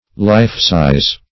lifesize - definition of lifesize - synonyms, pronunciation, spelling from Free Dictionary
\life"-sized`\(l[imac]f"s[imac]z`), a.